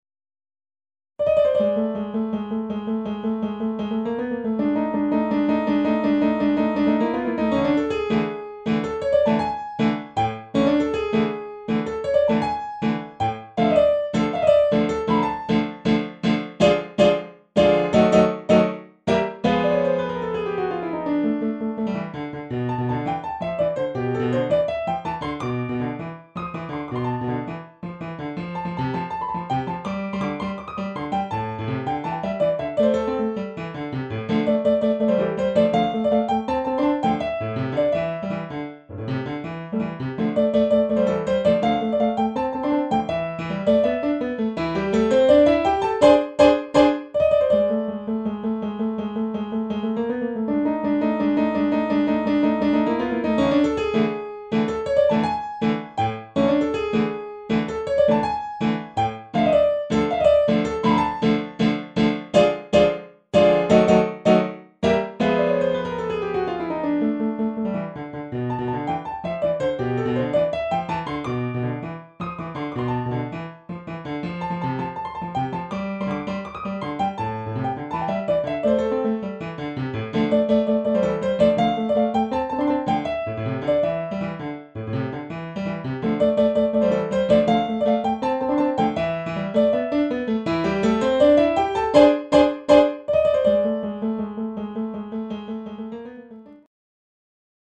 【ピアノ楽譜付き】